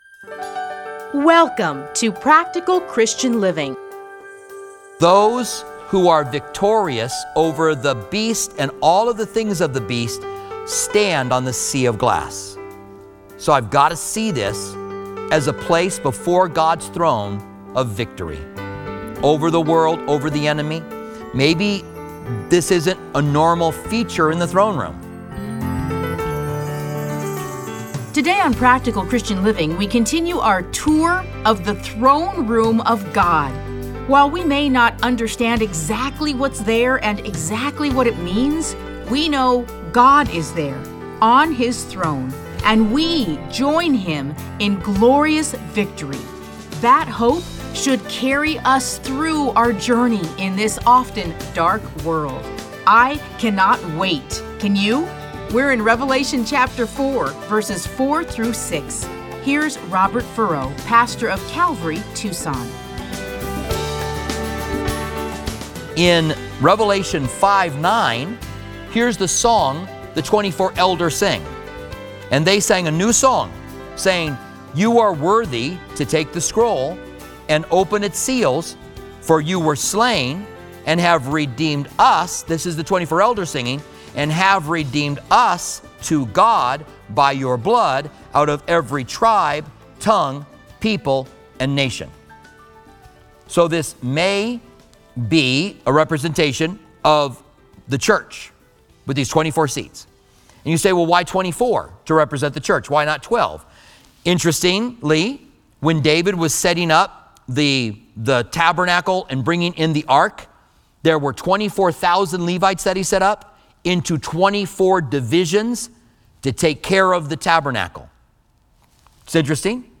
Listen to a teaching from Revelation 4:4-6.